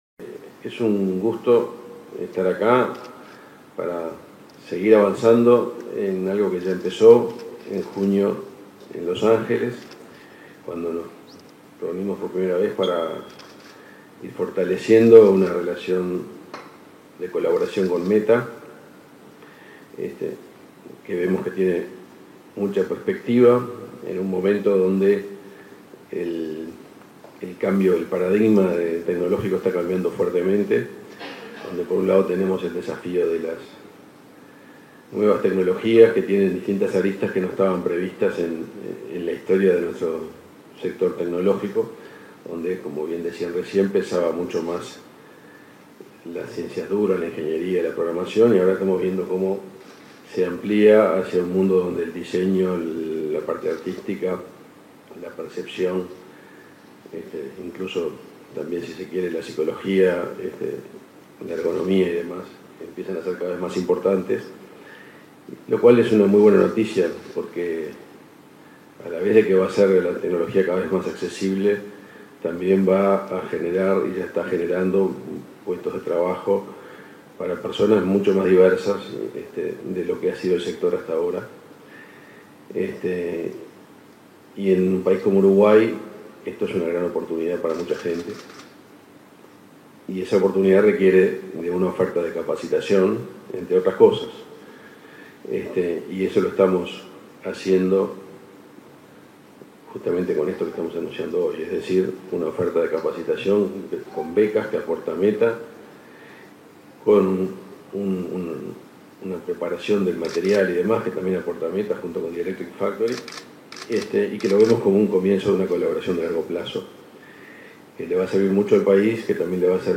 Palabras del ministro de Industria, Energía y Minería, Omar Paganini
Este 1.° de noviembre se realizó el acto de lanzamiento en Uruguay de Spark AR, una plataforma de realidad aumentada de la empresa Meta.